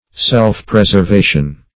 Self-preservation \Self`-pres`er*va"tion\, n.